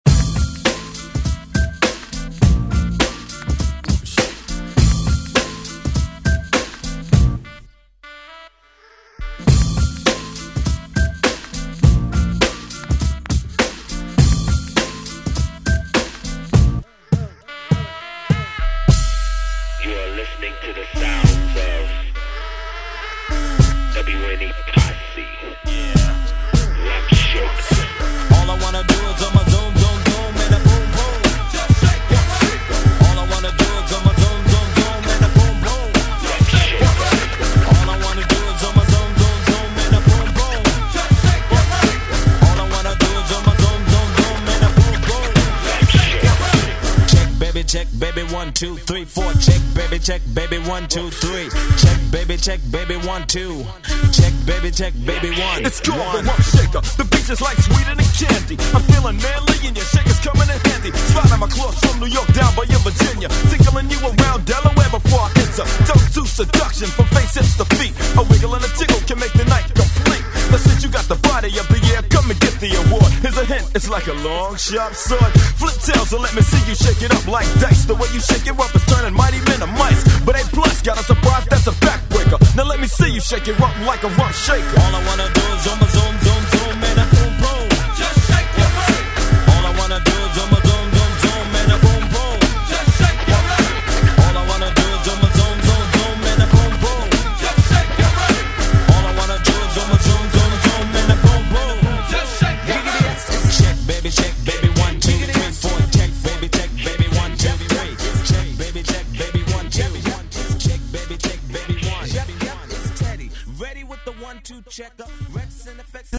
90s Throwback Electronic Euro House Music